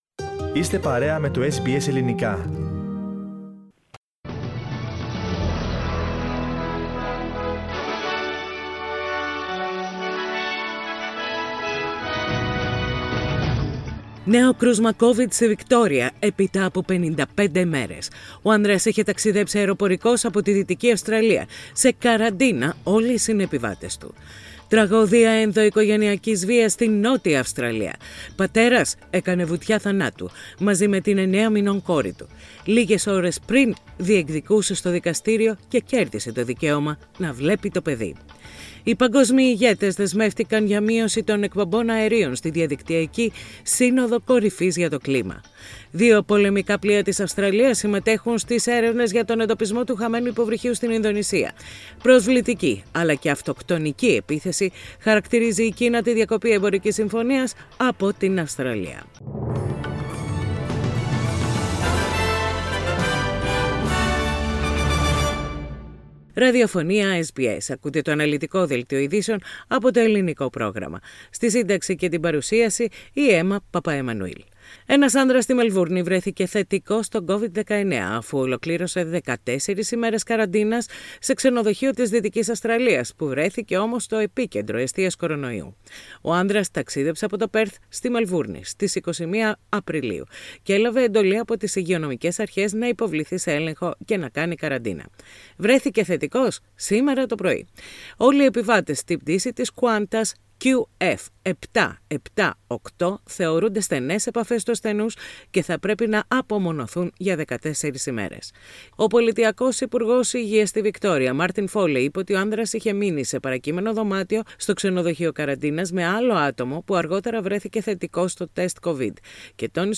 Πατήστε play στο podcast που συνοδεύει την αρχική φωτογραφία για να ακούσετε το αναλυτικό δελτίο ειδήσεων.